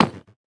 Minecraft Version Minecraft Version latest Latest Release | Latest Snapshot latest / assets / minecraft / sounds / block / nether_bricks / step2.ogg Compare With Compare With Latest Release | Latest Snapshot
step2.ogg